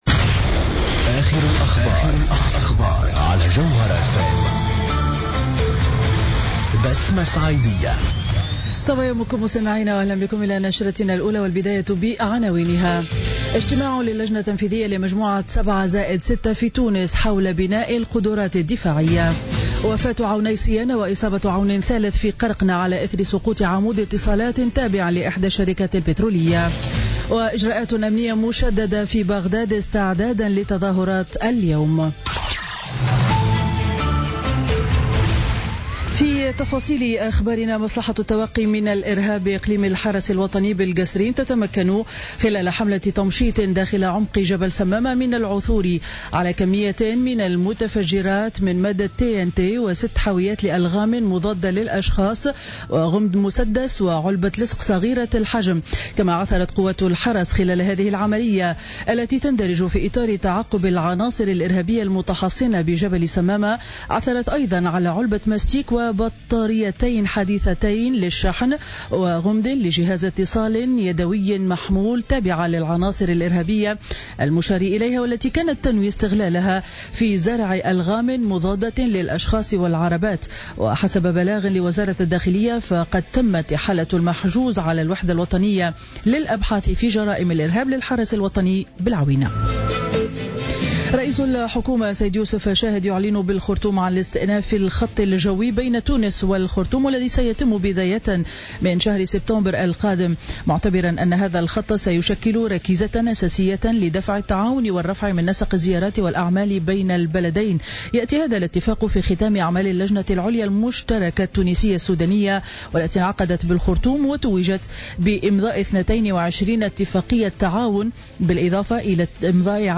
نشرة أخبار السابعة صباحا ليوم الجمعة 24 مارس 2017